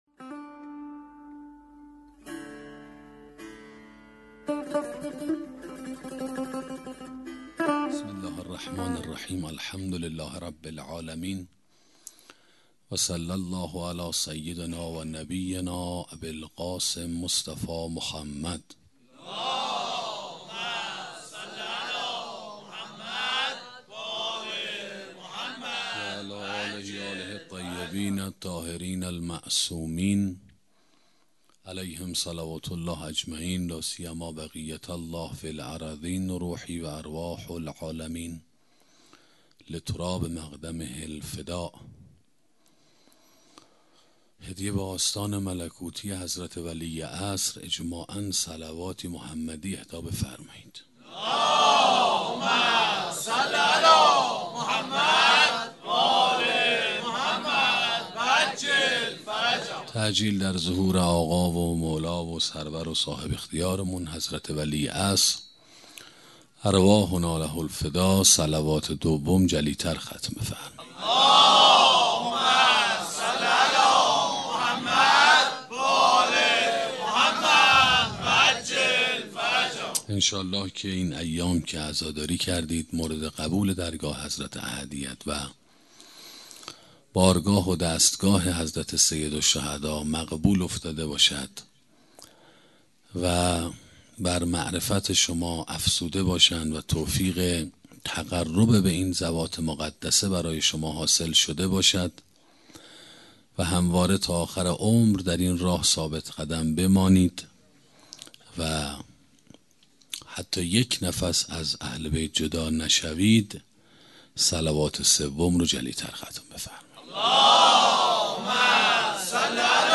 سخنرانی انتخاب و اختیار 2